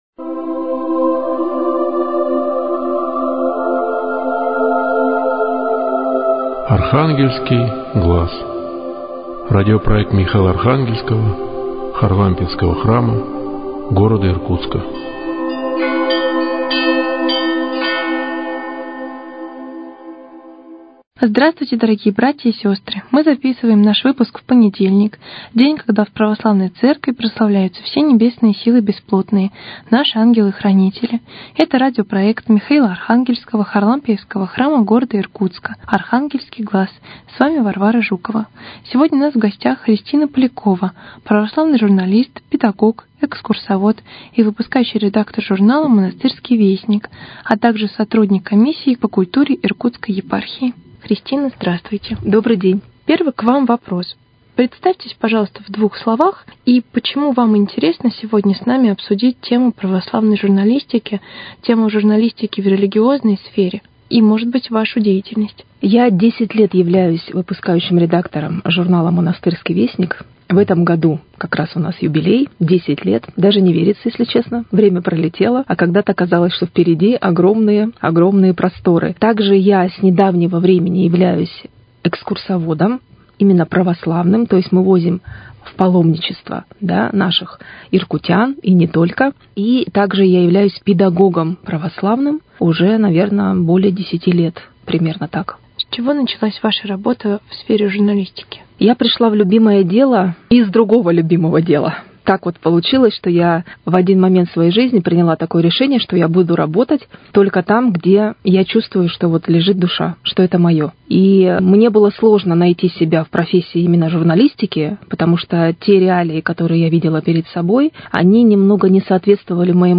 Интересная беседа